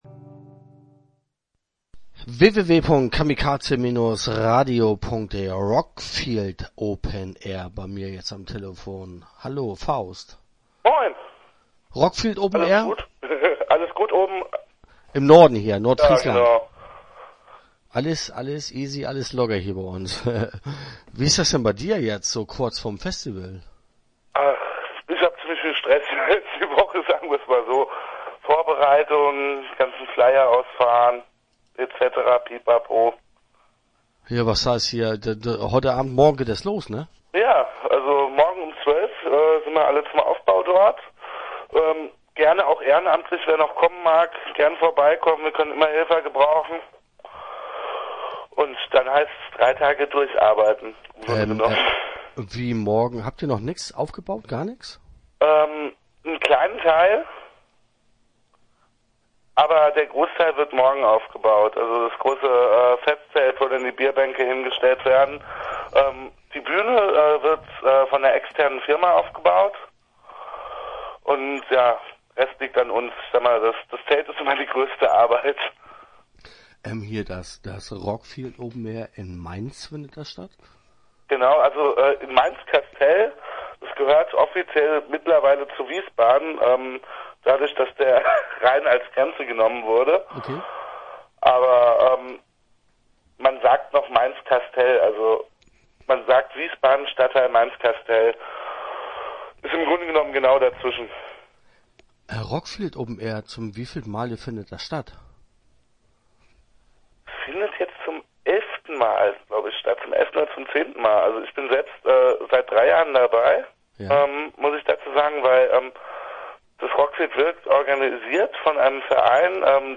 Interview Teil 1 (11:19)